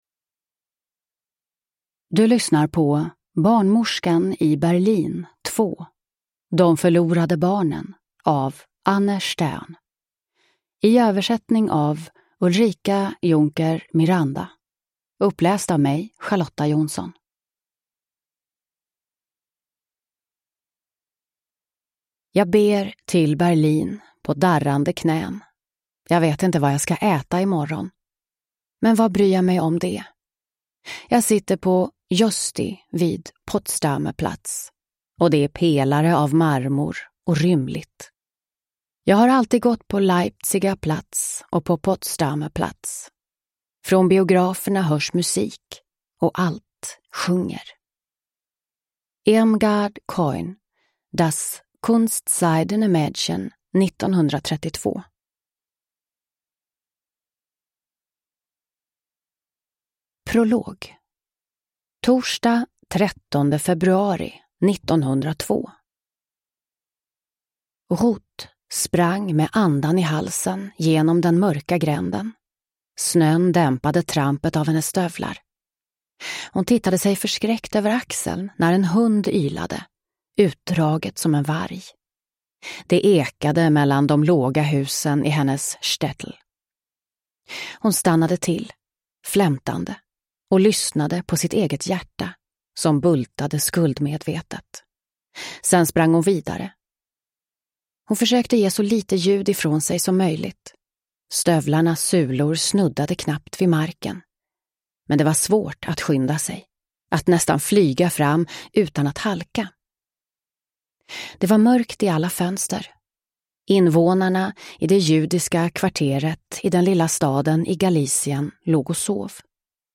De försvunna barnen – Ljudbok – Laddas ner